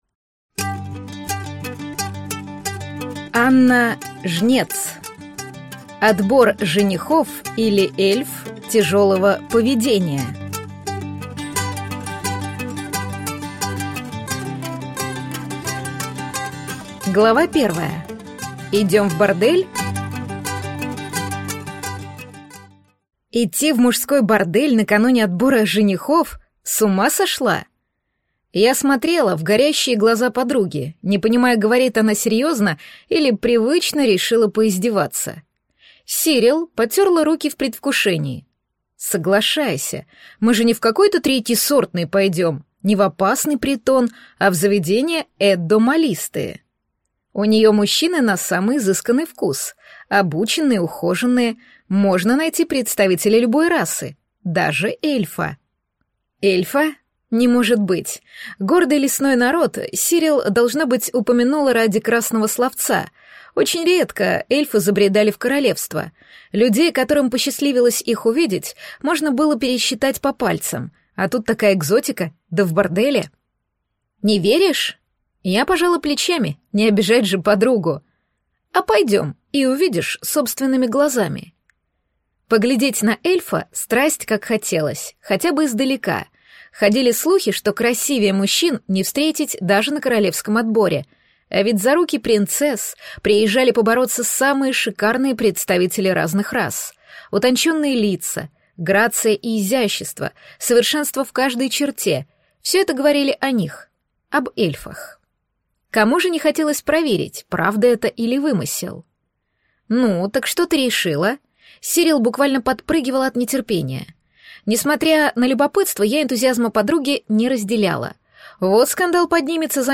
Аудиокнига Отбор женихов, или Эльф тяжелого поведения | Библиотека аудиокниг